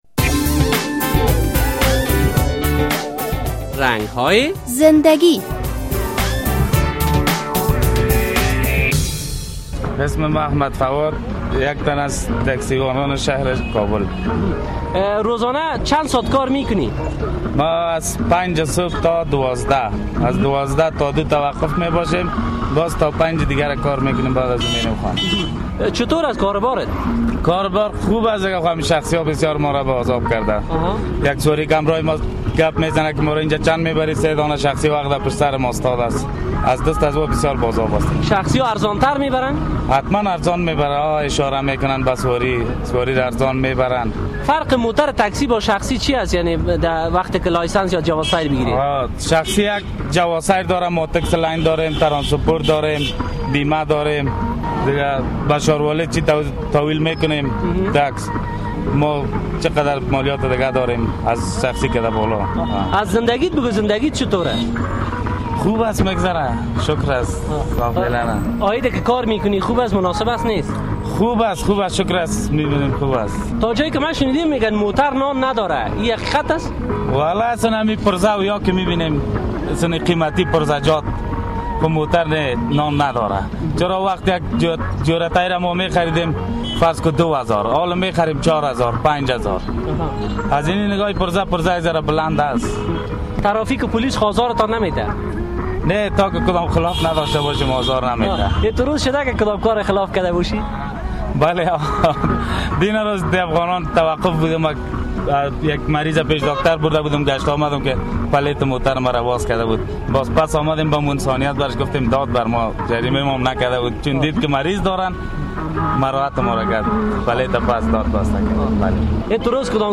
یک تن از راننده های تکسی در شهر کابل در برنامهء رنگ های زنده گی به خبرنگار صحبت کرده است.